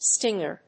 音節sting･er発音記号・読み方stɪ́ŋər
発音記号
• / stíŋɚ(米国英語)
• / stíŋə(英国英語)